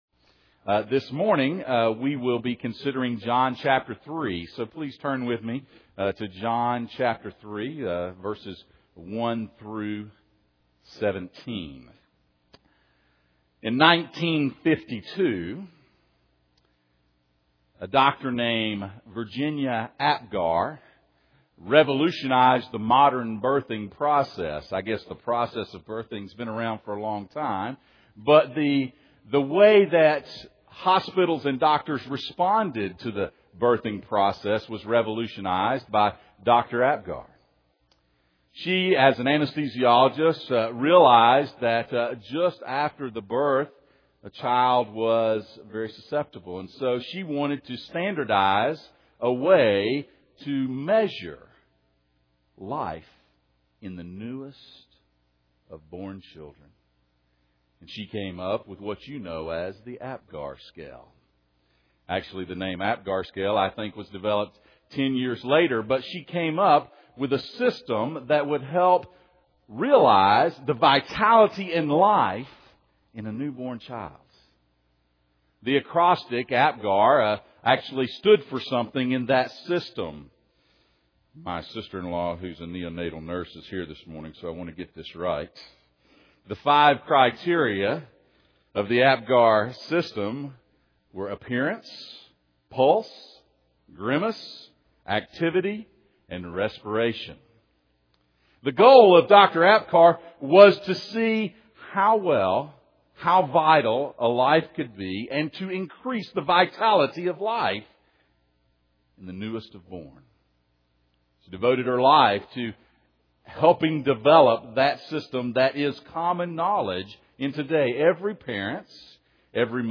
The Golden Chain Passage: John 3:1-17 Service Type: Sunday Morning « The Golden Chain